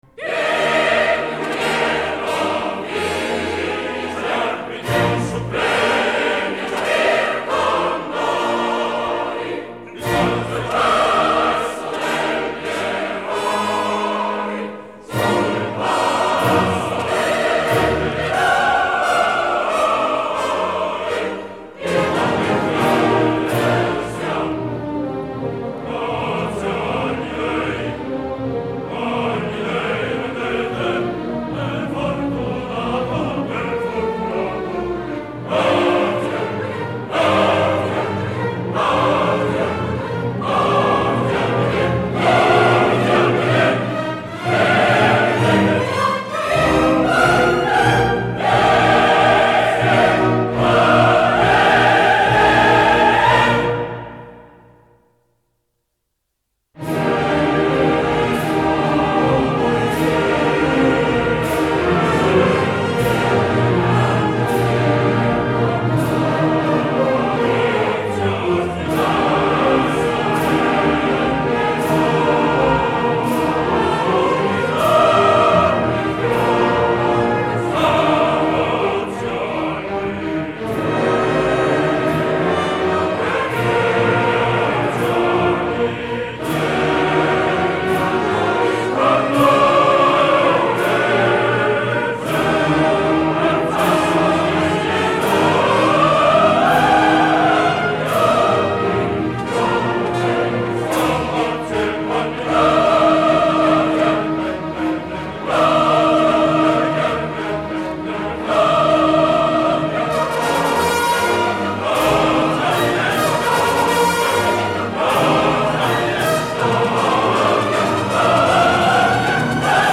Запись 3-16 IX. 1955, театр "Ла Скала", Милан.